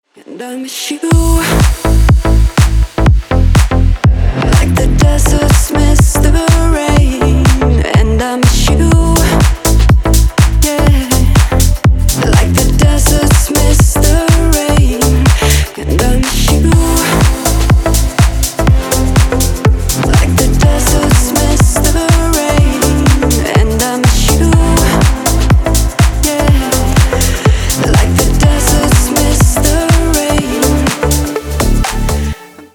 Танцевальное в формате рингтонов